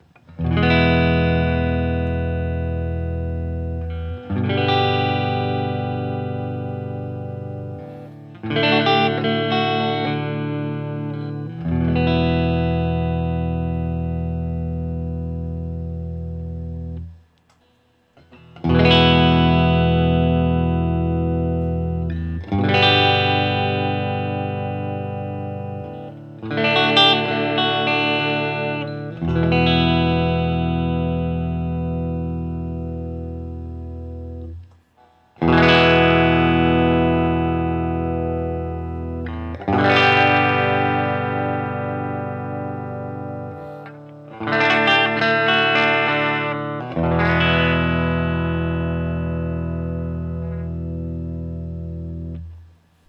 Open Chords #1
As usual, for these recordings I used my normal Axe-FX Ultra setup through the QSC K12 speaker recorded into my trusty Olympus LS-10.
For each recording I cycle through the neck pickup, both pickups, and finally the bridge pickup.
A guitar like this is really about that semi-hollow sound, and it delivers that in every position and on every fret, though that sort of hollow timbre can obviously get lost when the gain is up high and the effects are set to overwhelming.